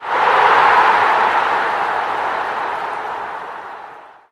snd_audience_fg.ogg